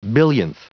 Prononciation du mot billionth en anglais (fichier audio)